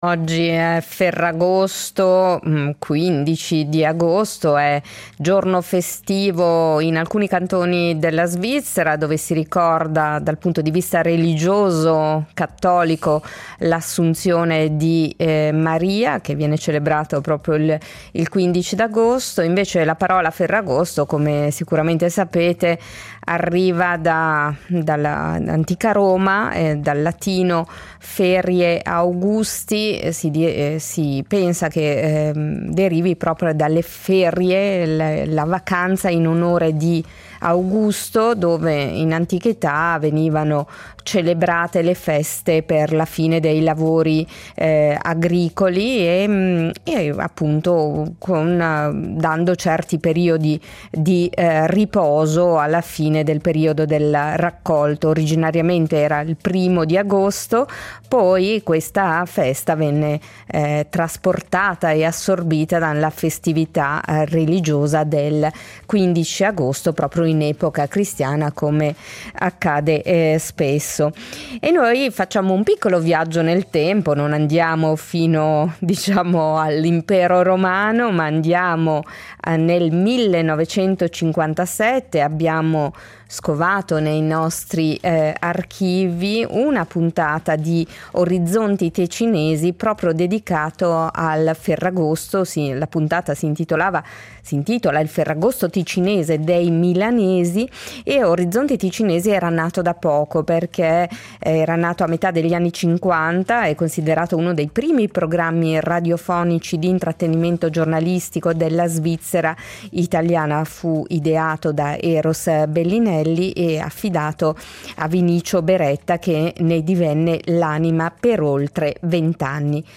I frammenti tratti dall’archivio RSI con la trasmissione “Orizzonti ticinesi” ci portano al 15 agosto del 1957.